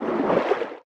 Sfx_creature_trivalve_swim_slow_05.ogg